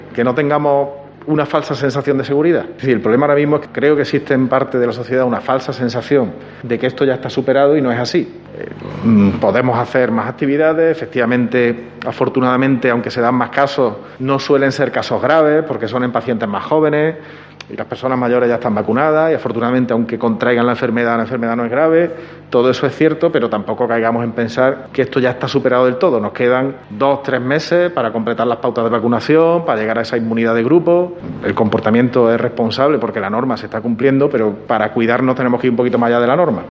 En una rueda de prensa, el regidor ha subrayado que "ahora la incidencia está subiendo y eso tiene que llevar a la reflexión de que si hay más incidencia, hay más riesgo estadísticamente de contagiarse".